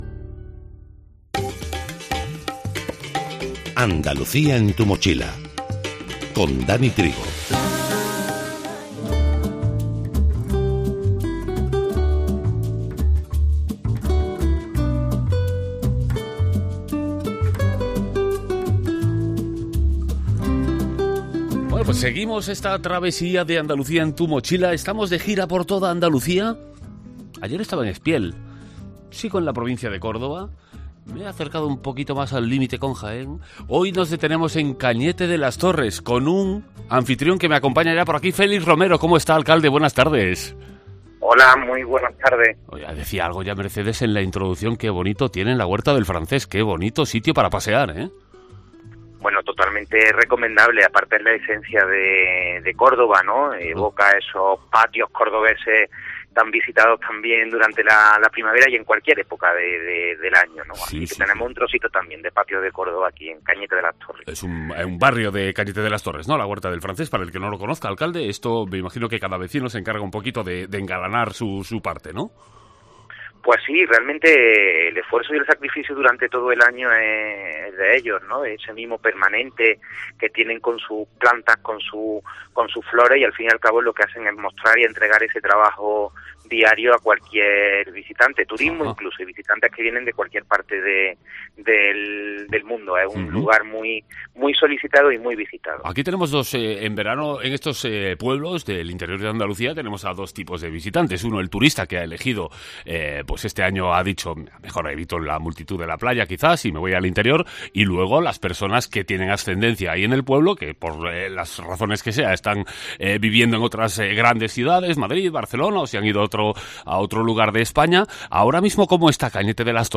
Escucha a Félix Romero, alcalde de Cañete de las Torres